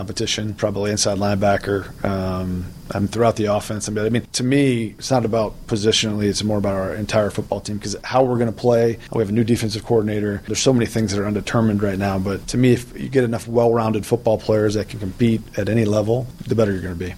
Gutekunst talks at NFL Scouting Combine: Packers GM Brian Gutekunst met with the media yesterday at the NFL Scouting Combine.